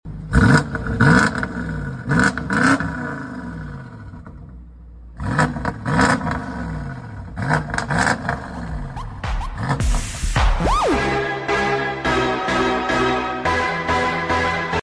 IS THIS THE BEST SOUNDING BMW X7 IN THE WORLD? 🔊 Rate in the comments ⬇ Interested in upgrading your BMW X7/X7M?